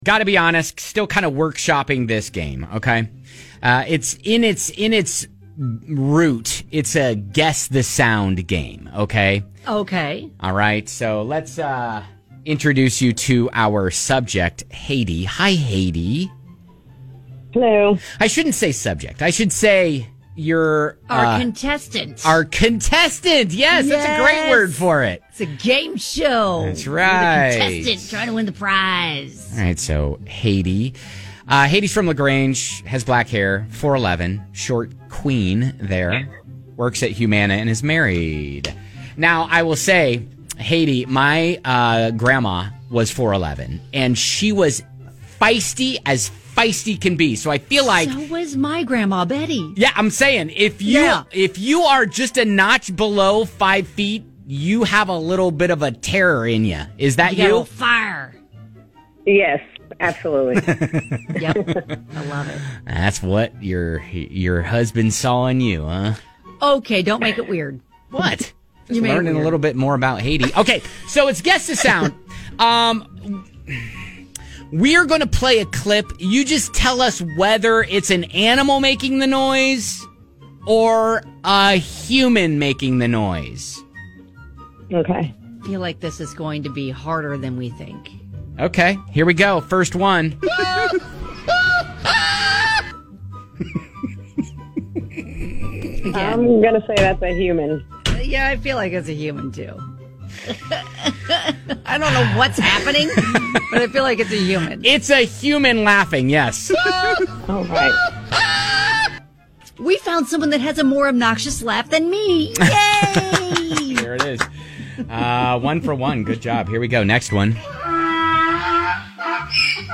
We found some random clips on the internet and you just need to guess whether the sound you hear is that of a human or animal?